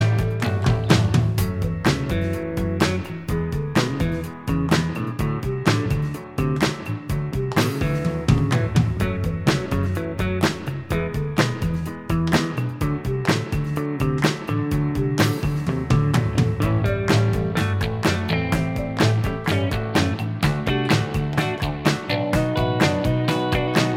Minus Acoustic Guitar Rock 3:39 Buy £1.50